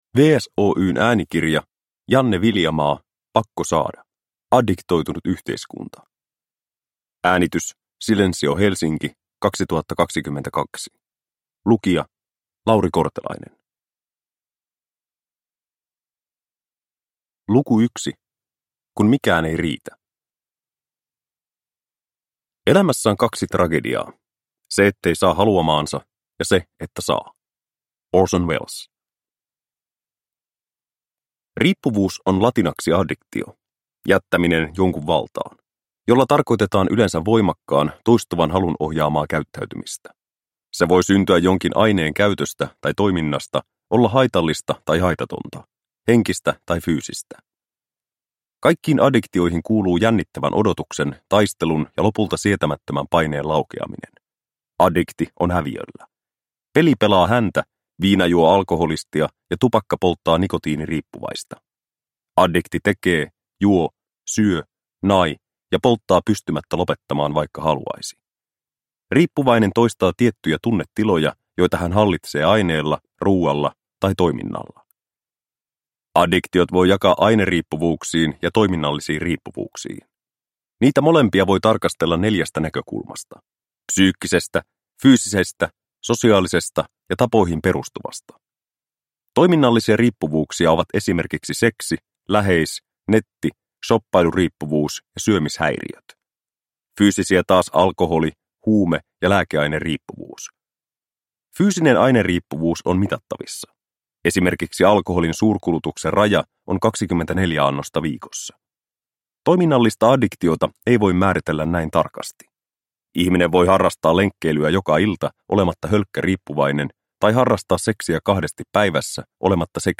Pakko saada! – Ljudbok – Laddas ner